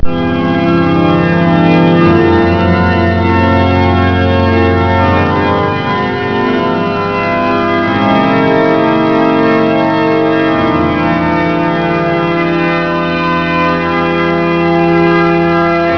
The facade of the cathedral with the organ sound
Nidaros Cathedral, Trondheim, Norway